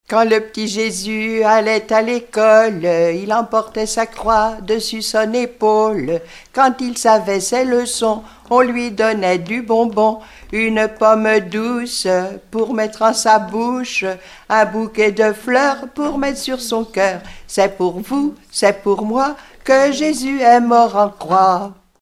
Sigournais
Enfantines diverses
Pièce musicale éditée